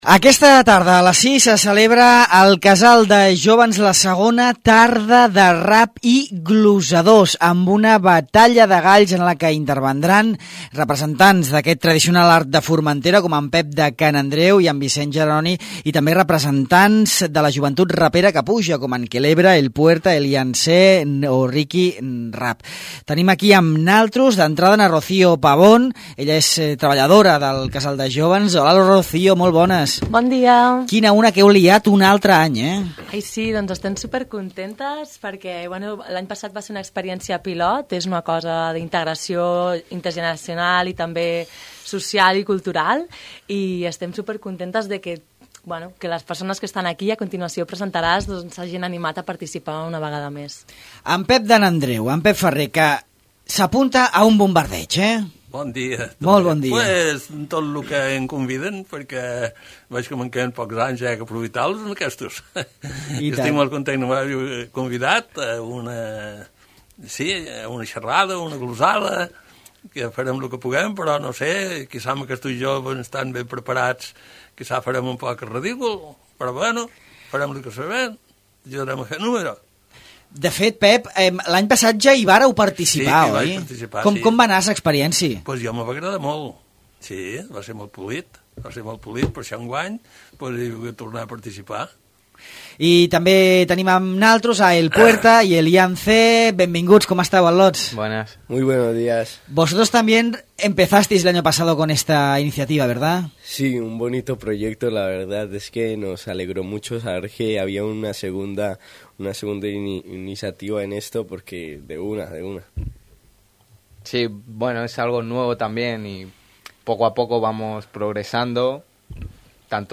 El rap i les glosses tradicionals es troben al Casal de Joves